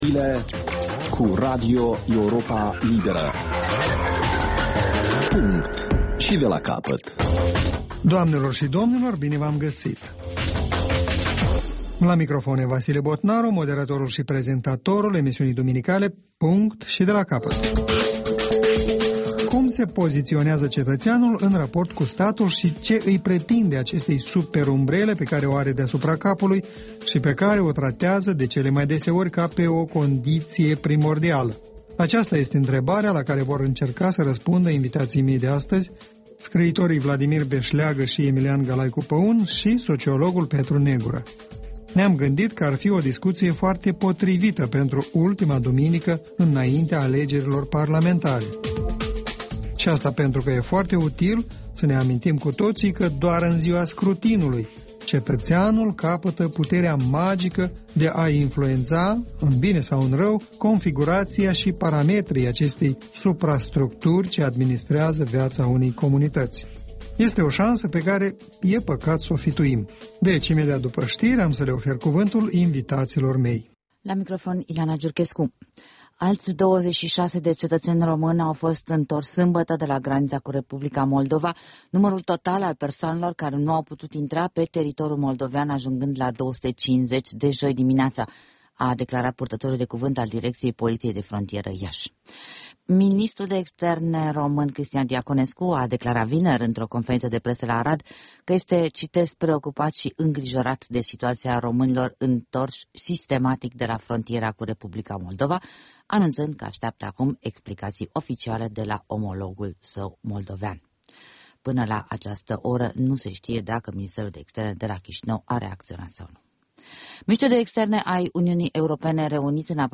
Masă rotundă cu participarea scriitorilor Vladimir Beşleagă, Emilian Galaicu Păun